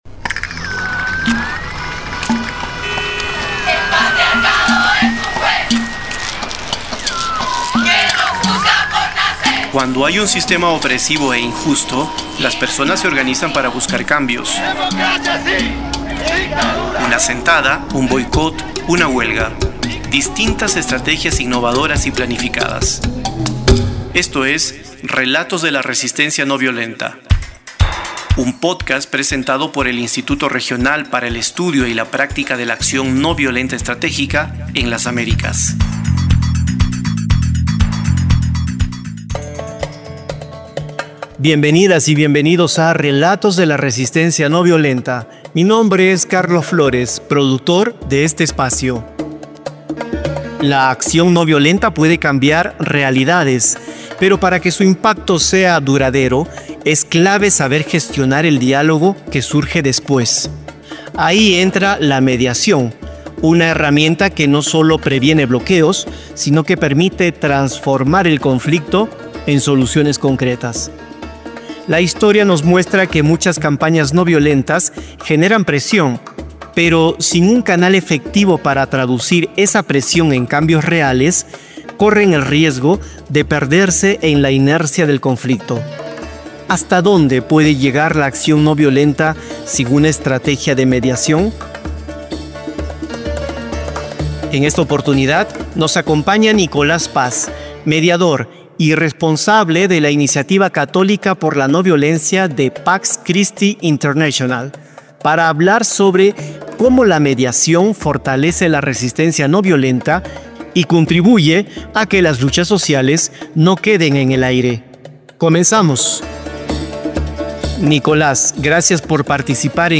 Archivo Descripción Tamaño Formato AFLACSO-Paz-2025.jpg Afiche - Acción Noviolenta 200,71 kB JPEG Visualizar/Abrir AFLACSO-Paz-2025.mp3 Entrevista - Acción Noviolenta 41,27 MB audio/basic Visualizar/Abrir